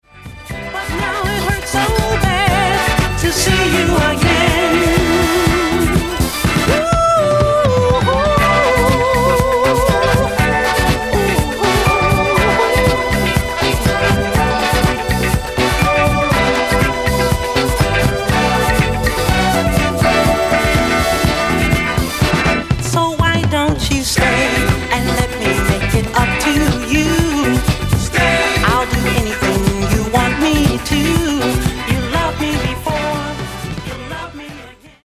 Genere:   Disco|Soul | Funky